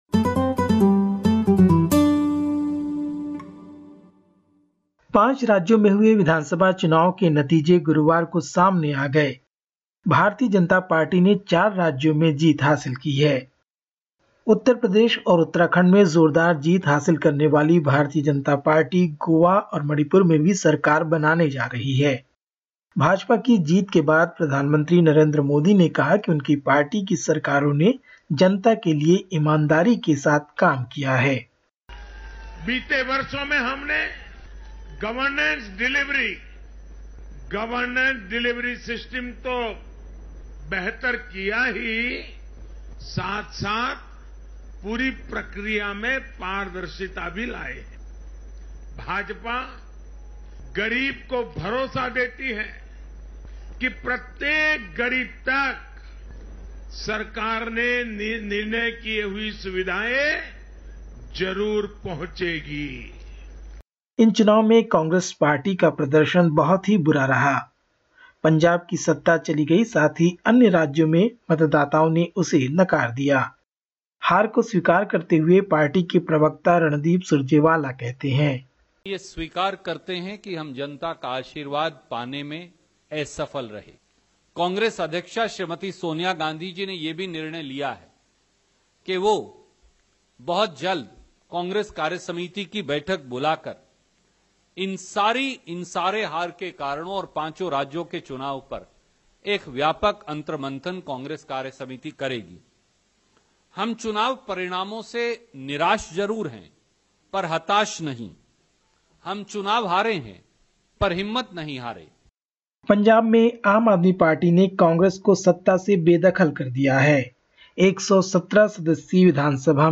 Listen to the latest SBS Hindi report from India. 11/03/2022
In this news bulletin: